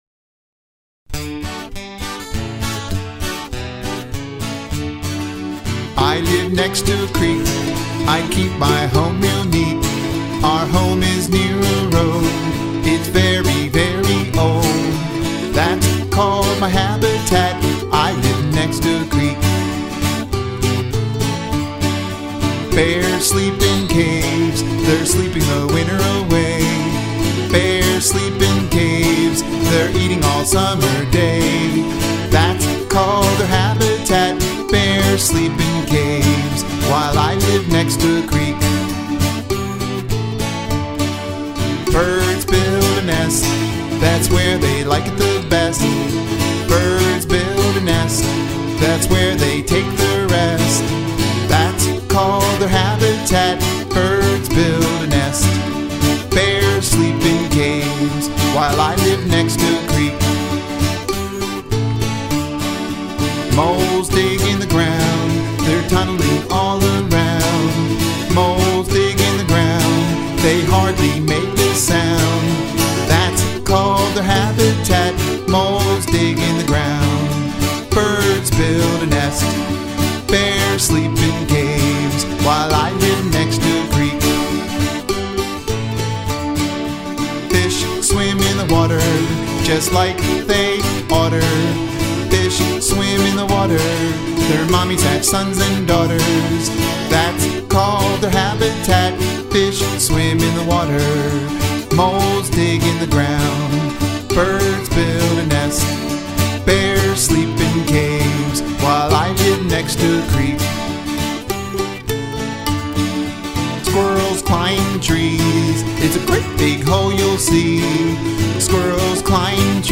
Habitat destruction is the the number one cause of loss of a species. This is a highly adaptable song for teachers that are teaching young children about habitats.